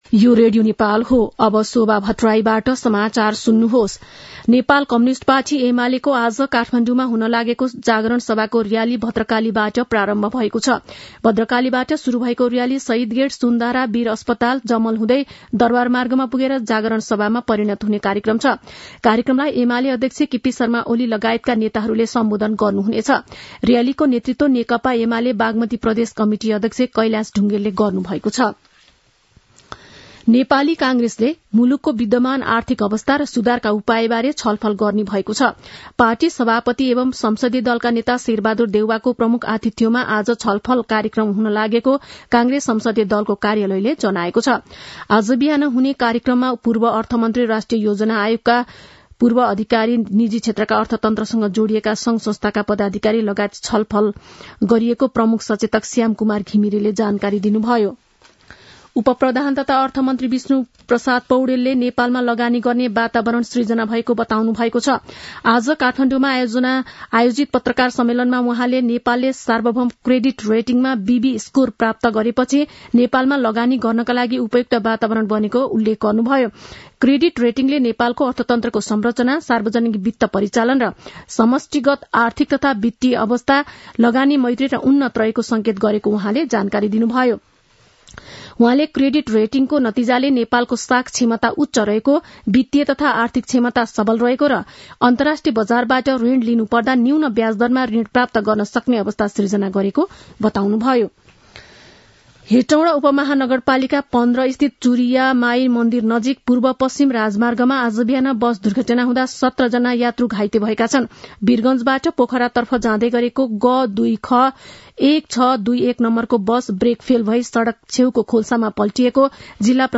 दिउँसो १ बजेको नेपाली समाचार : ८ मंसिर , २०८१
1-pm-nepali-news-1-6.mp3